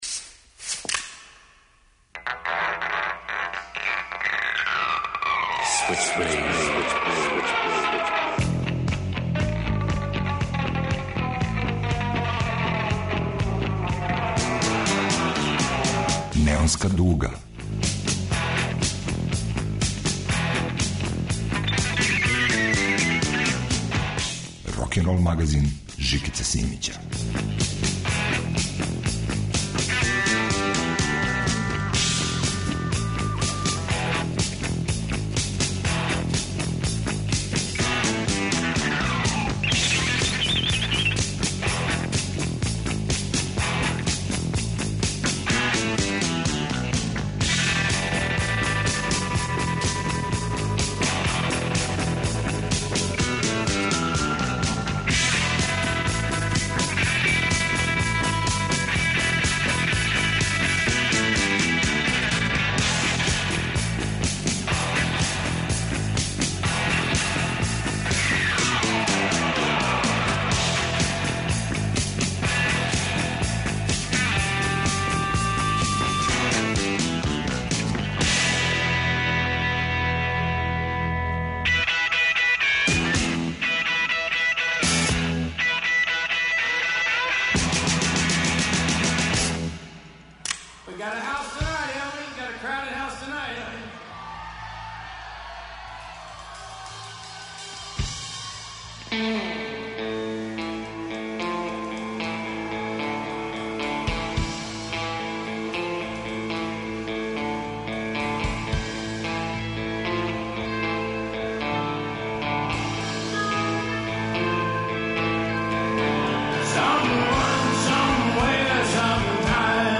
Психоделични блуз, звучне катедрале и соногови из позоришта сенки.